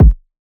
Kick.wav